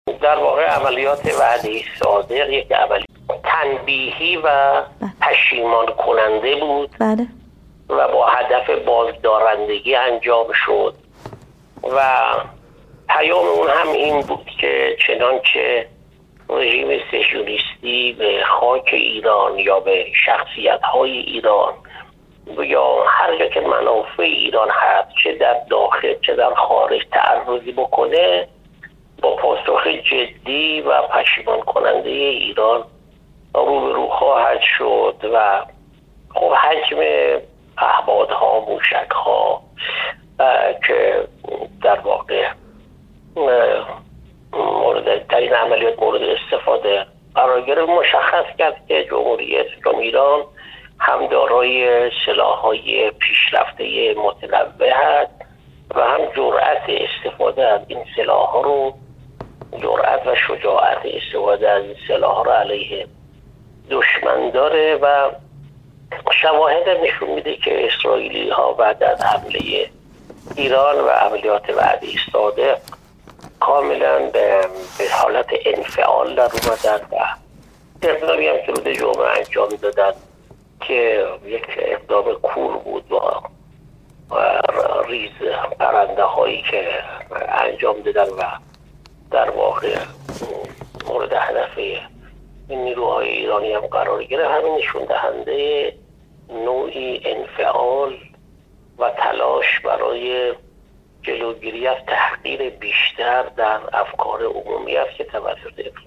محسن پاک‌آیین، دیپلمات ارشد کشورمان و سفیر پیشین جمهوری اسلامی ایران در جمهوری آذربایجان، در گفت‌وگو با ایکنا در پاسخ به این سؤال که عملیات وعده صادق تا چه اندازه معادلات کلی منطقه را تغییر داده است؟ گفت: وعده صادق، یک عملیات تنبیهی و پشیمان‌کننده بود که با هدف بازدارندگی انجام شد.